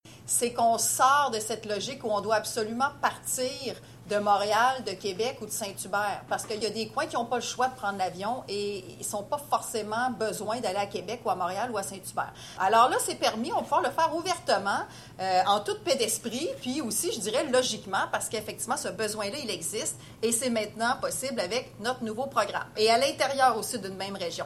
La ministère Geneviève Guilbault, lors de l’annonce (capture d’écran Youtube Télé-Gaspé)